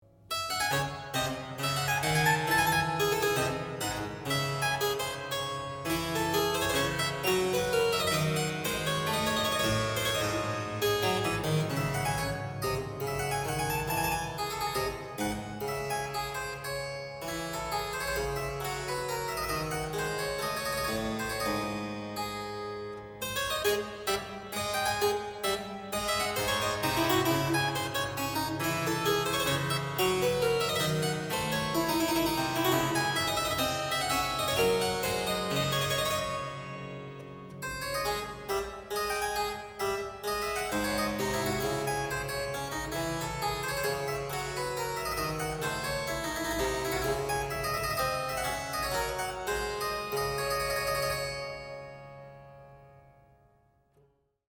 mp3Seixas, Carlos de, Sonata No. 27 in D minor, mvt.
Minuet